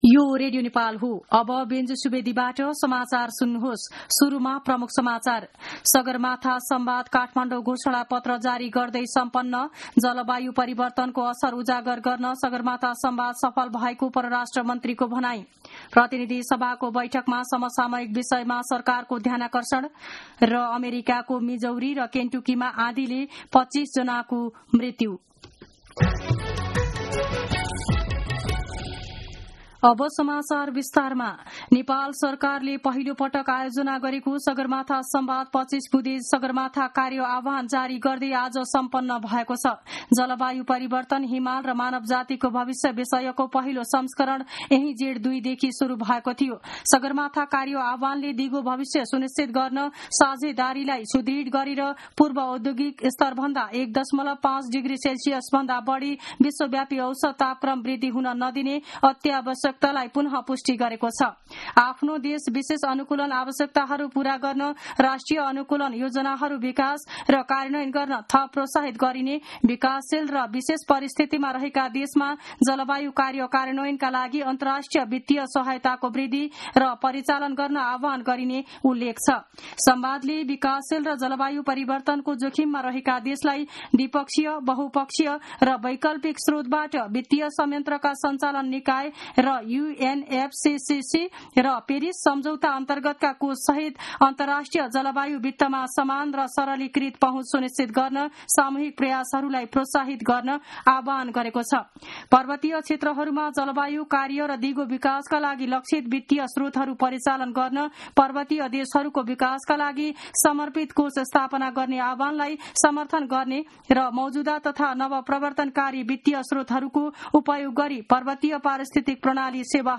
दिउँसो ३ बजेको नेपाली समाचार : ४ जेठ , २०८२
3-pm-news-1-3.mp3